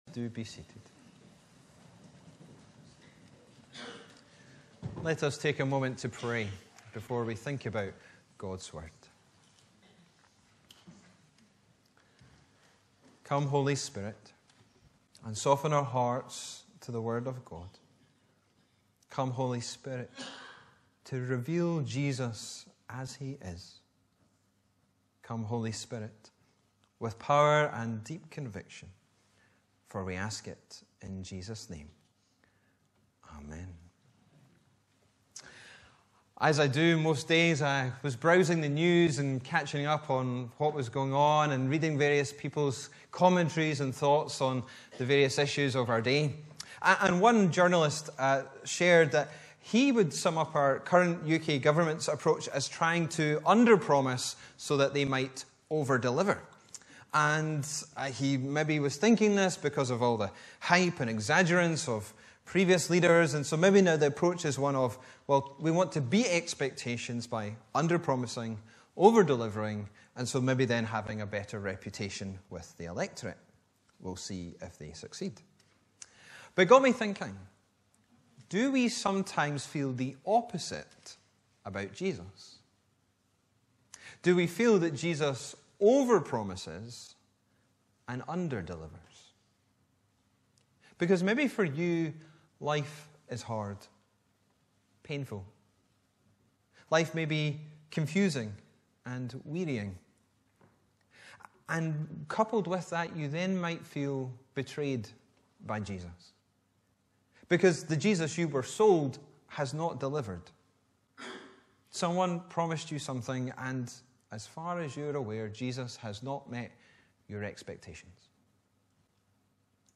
Bible references: John 5:1-18 Location: Brightons Parish Church Show sermon text Sermon keypoints: -